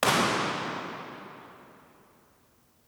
Description: Loudspeaker comparison made in reverberant church. Distances are 6 m and 12 m.
File Type: XY Cardioid Stereo
IR_TP2_Balloon_12m.wav